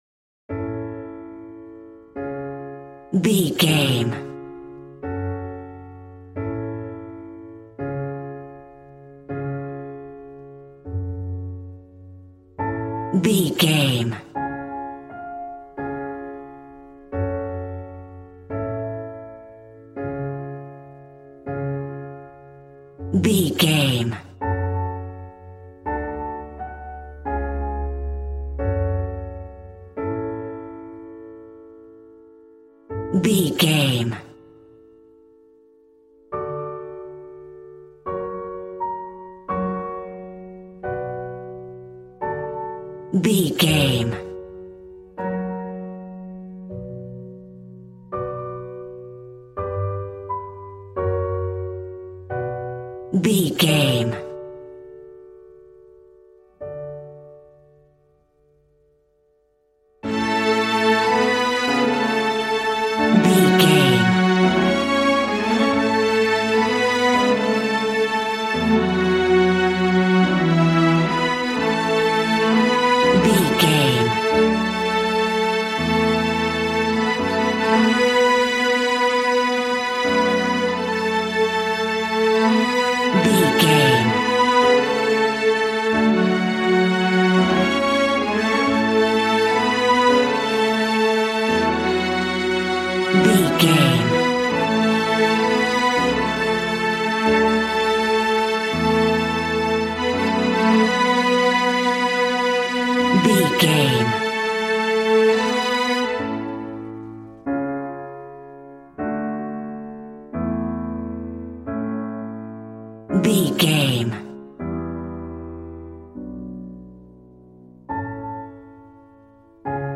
Regal and romantic, a classy piece of classical music.
Aeolian/Minor
A♭
regal
strings
violin